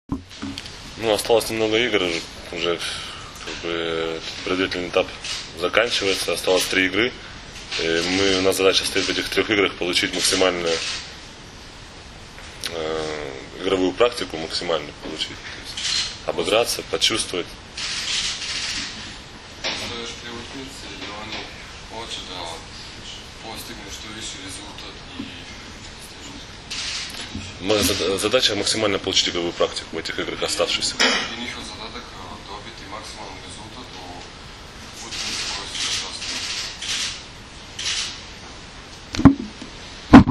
Danas je u Medija centru SPC „Vojvodina“ u Novom Sadu održana konferencija za novinare, kojoj su prisustvovali kapiteni i treneri Kube, Srbije, Rusije i Japana
IZJAVA